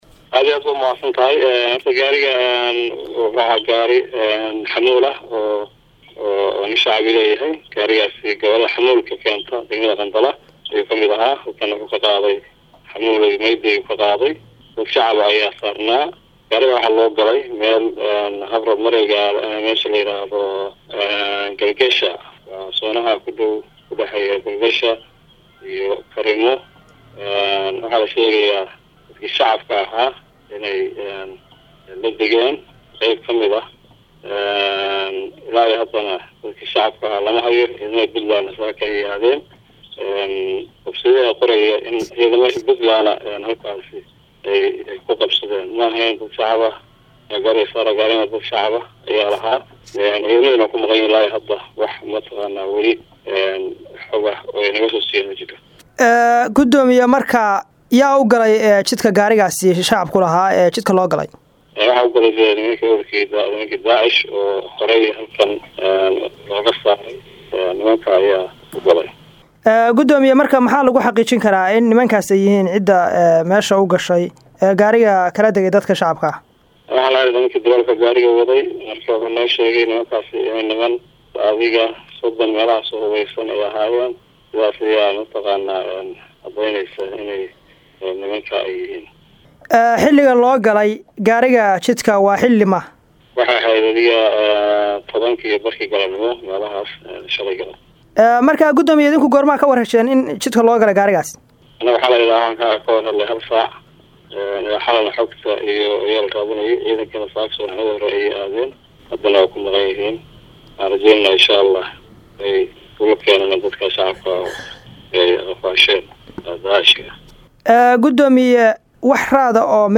Hoos ka Dhagayso Gudoomiyaha degmada Qandala ee Gobolka Bari Jaamac  Maxammed (Quurshe)